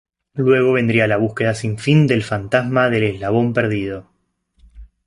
es‧la‧bón
/eslaˈbon/